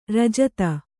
♪ rajata